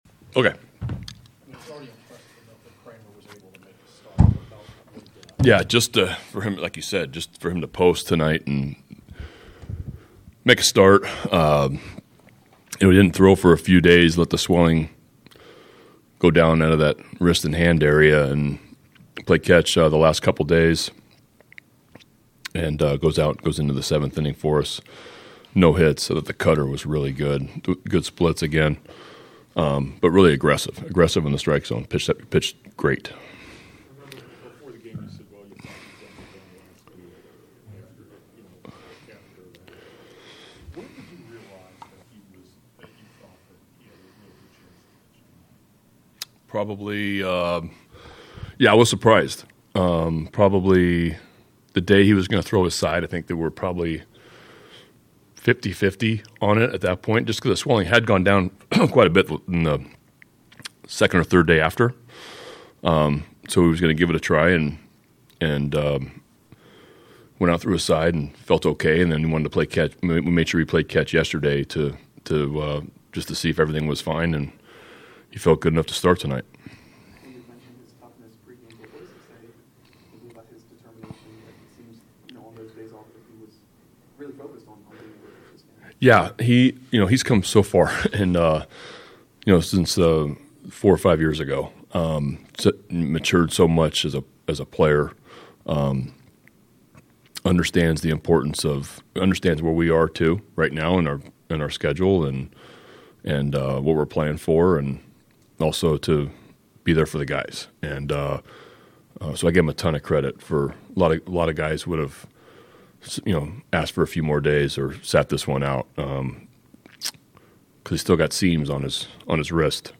Locker Room Sound
Brandon Hyde meets with media following Orioles' 2-0 win over Rays